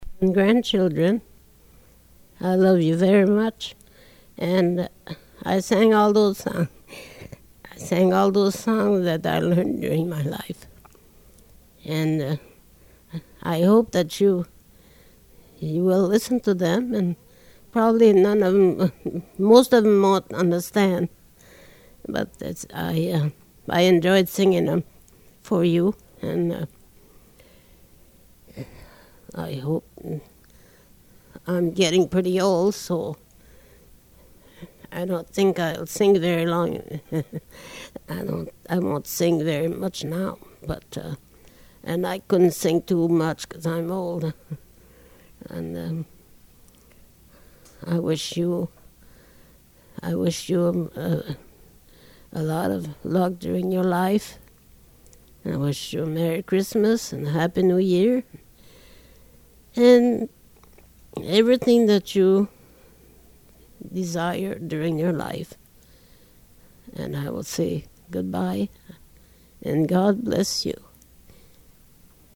Original Format sound cassette (analog)